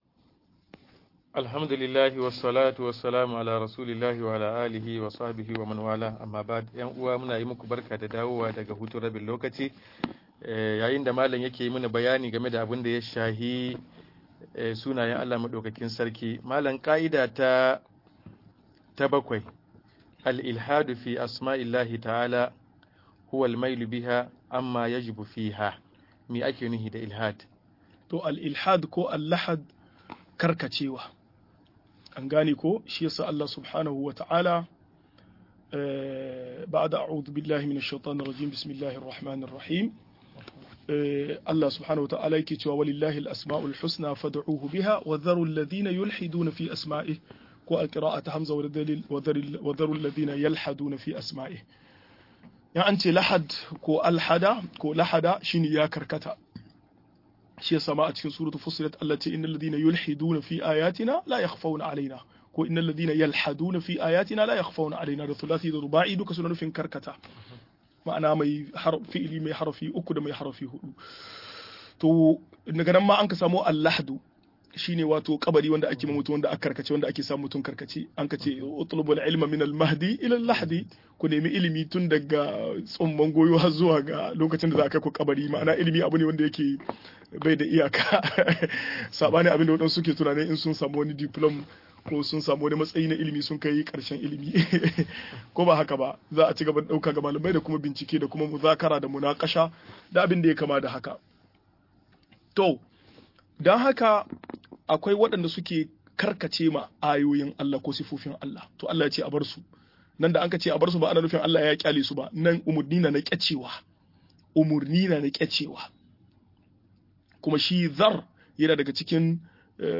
Sunayen Allah da siffofin sa-02 - MUHADARA